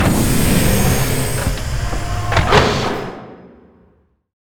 taxiopen.wav